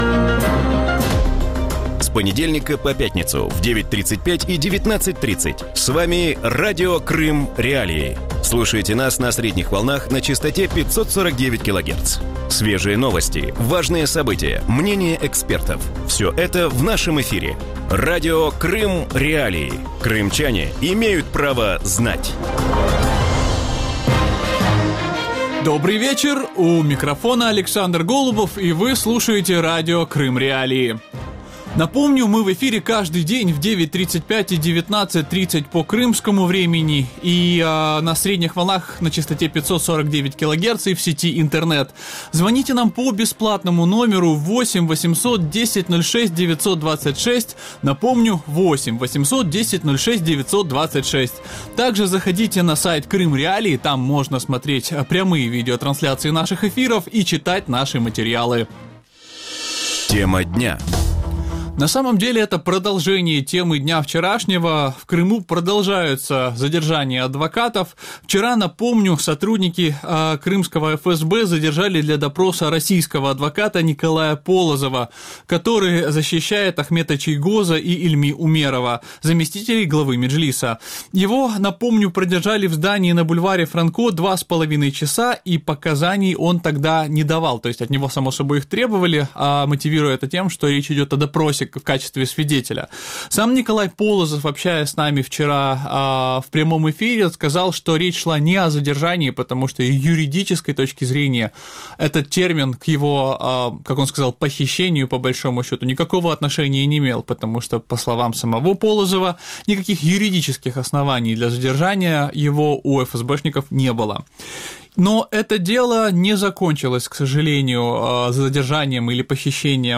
У вечірньому ефірі Радіо Крим.Реалії говорять про діяльність міжнародних організацій по Криму і ефективність їх дій. Як міжнародні організації можуть реагувати на порушення прав людини в анексованому Криму і якими засобами володіють місцеві правозахисники?